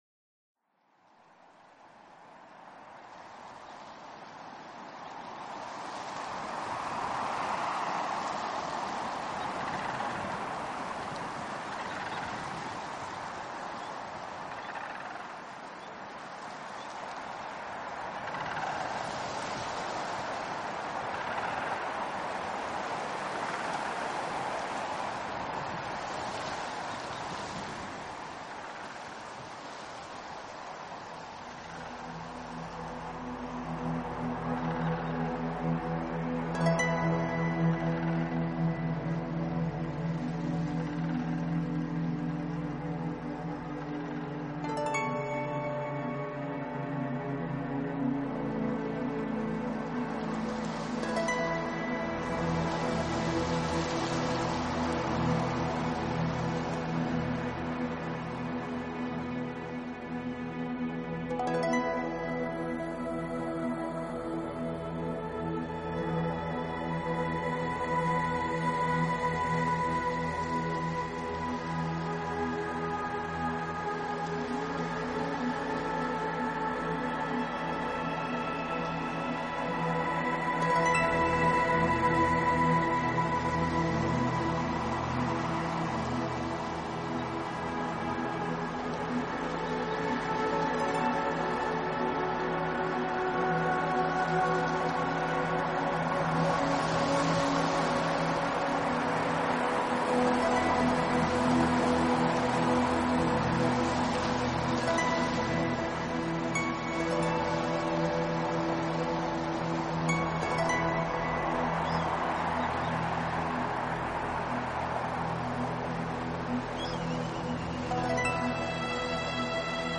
【新世纪音乐】
End，冰层融水的流动声贯穿其中。